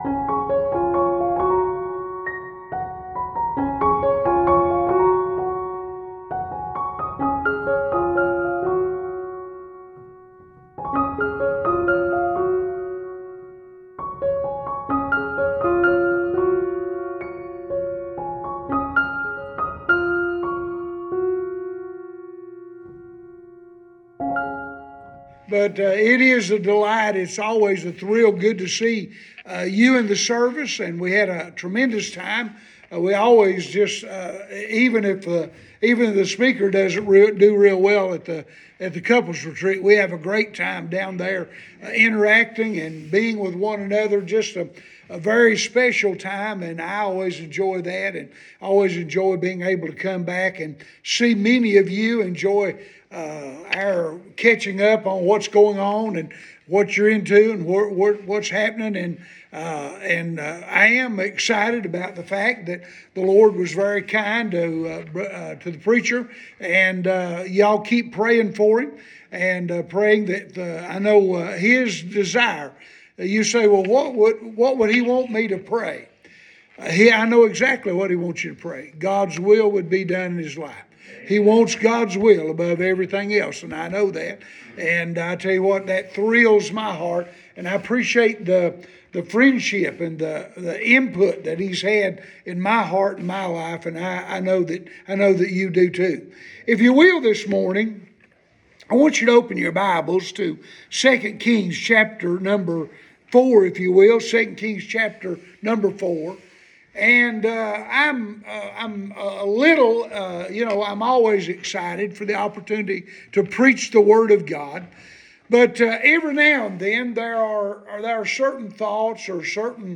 Sunday Morning – April 16, 2023